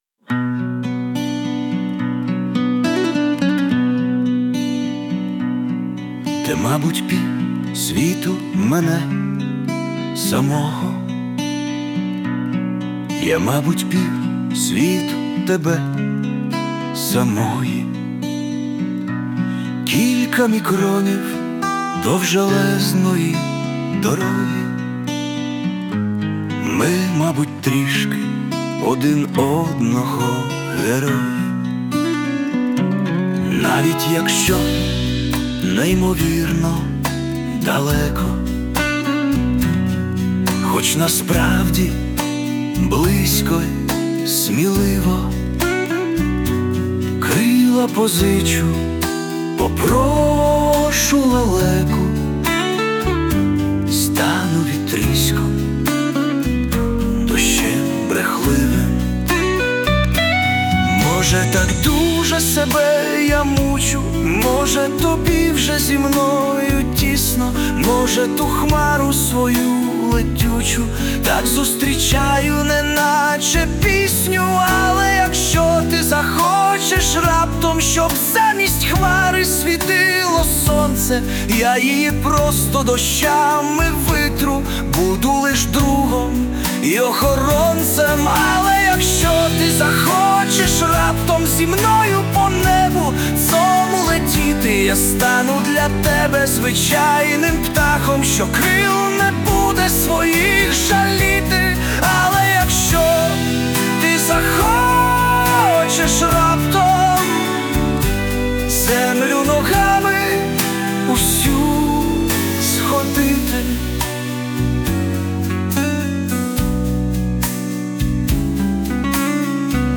Музичний супровід створено з допомогою ШІ
СТИЛЬОВІ ЖАНРИ: Ліричний
я зробив нову версію ... як пісню